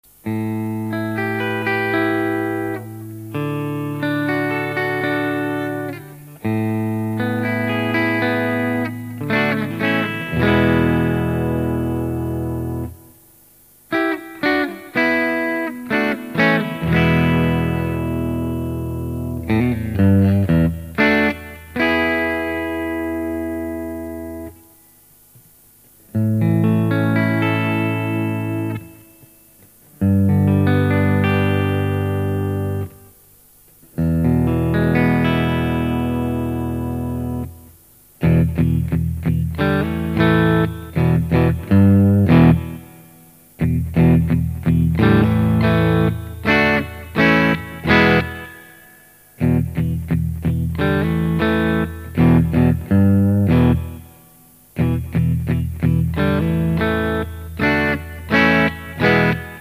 The Titan is even louder than the Behemoth, with well balanced tone.
It has plenty of top end with the neck pickup particularly sweet sounding. The bridge pickup can be used just as effectively for gentle pop tones as for hard rock and metal.